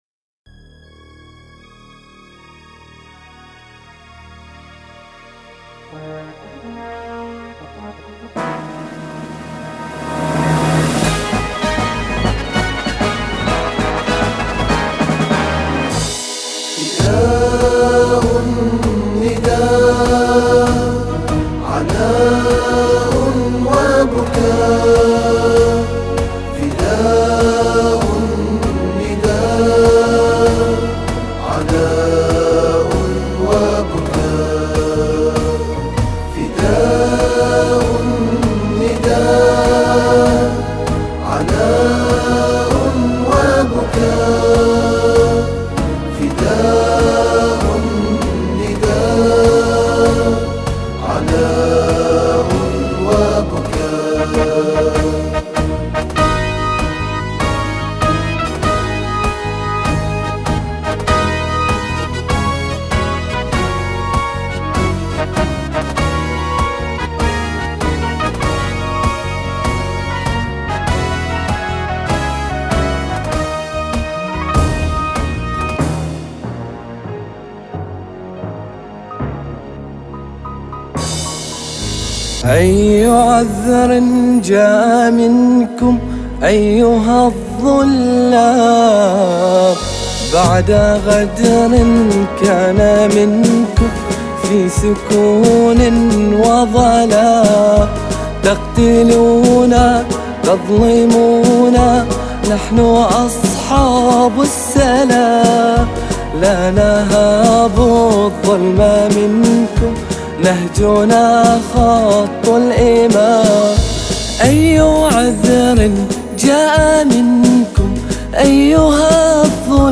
أناشيد بحرينية انشودة وطنية